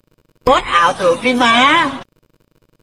This sound effect is very hot, suitable for creating entertainment highlights, making people laugh and increasing interaction for your content.